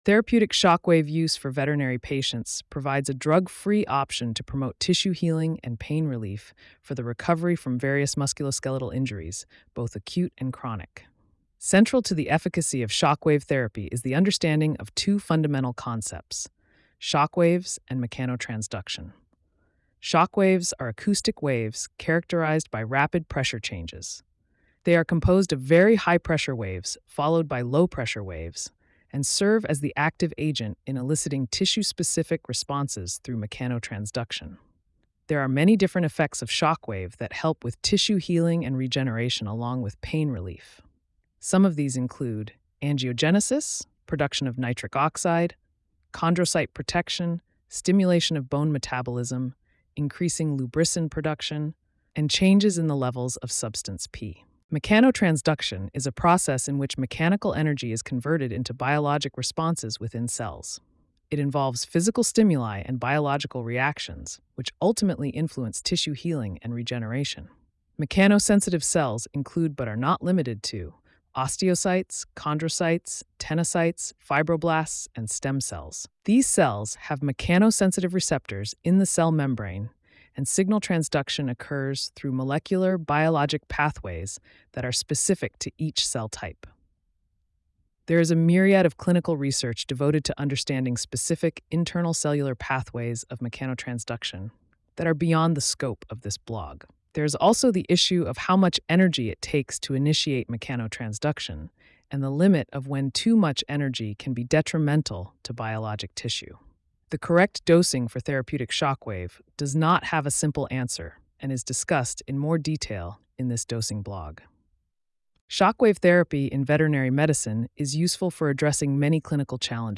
Mechanotransduction Blog Narration.mp3